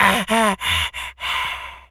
pgs/Assets/Audio/Animal_Impersonations/monkey_hurt_slow_death_03.wav at master
monkey_hurt_slow_death_03.wav